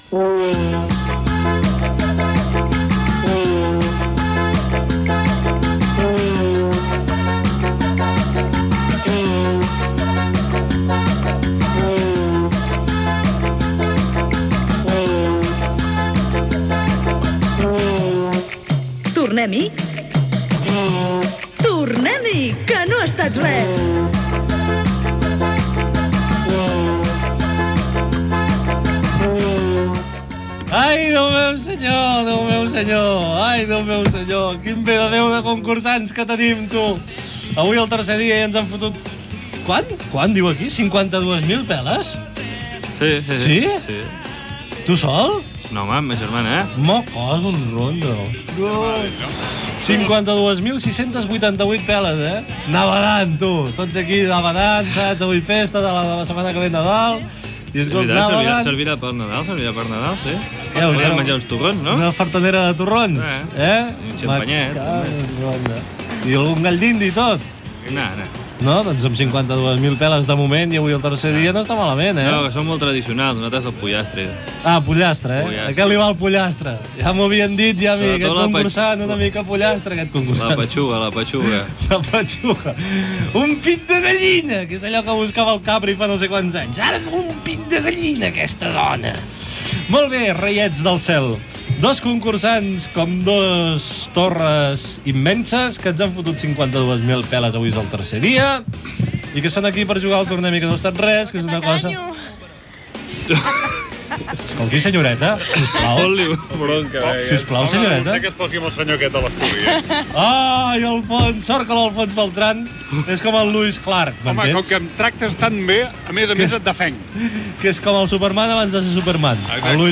Inici del programa, quantitat de diners guanyada, presentació dels concursants, indicatiu, primer bloc de preguntes, indicatiu de l'emissora, segon bloc de preguntes Gènere radiofònic Entreteniment